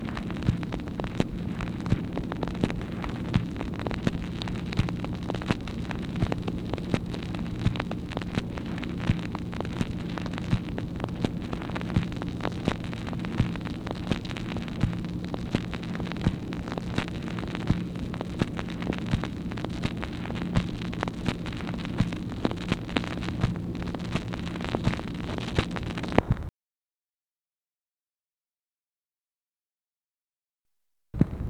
MACHINE NOISE, April 7, 1964
Secret White House Tapes | Lyndon B. Johnson Presidency